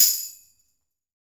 Tamborine2.wav